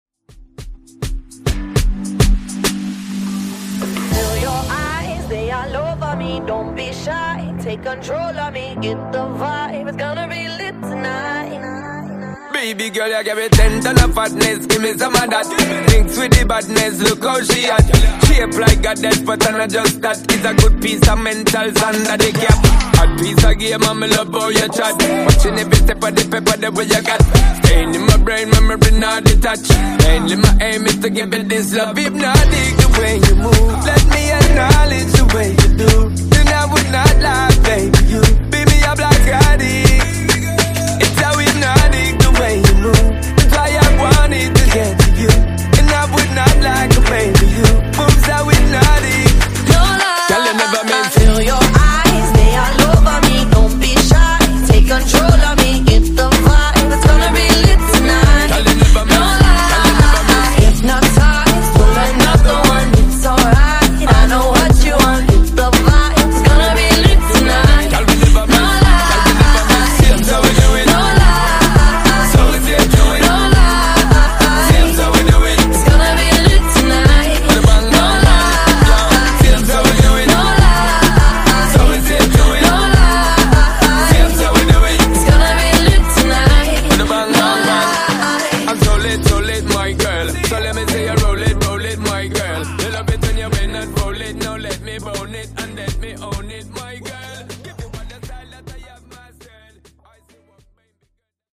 Genres: RE-DRUM , REGGAETON
Clean BPM: 102 Time